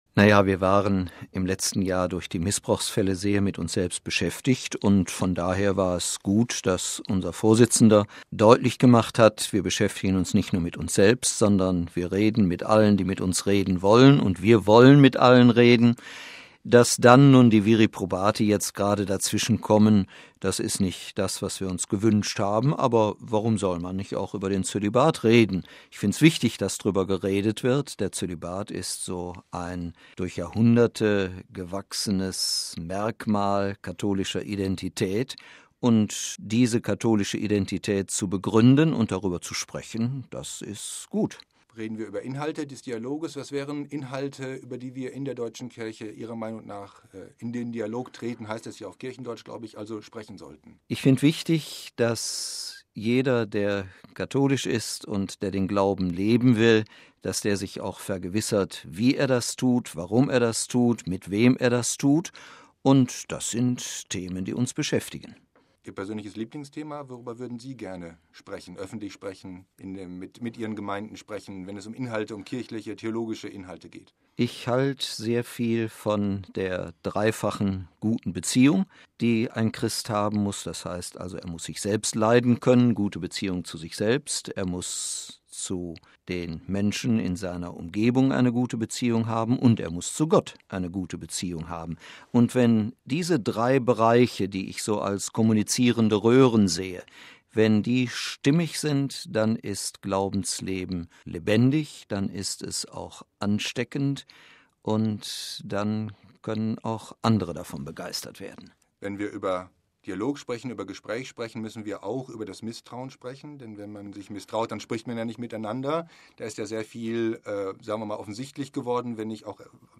Interview der Woche - mit Hamburgs Erzbischof Thissen
Im Gespräch mit Radio Vatikan sagte Thissen bei einem Besuch in Rom: „Der Zölibat ist so ein durch Jahrhunderte gewachsenes Merkmal katholischer Identität, und diese katholische Identität zu begründen und darüber zu sprechen, das ist gut!“ Thissen ist als Bischof einer der Verantwortlichen für den Dialogprozess, den die Deutsche Bischofskonferenz angestoßen hat.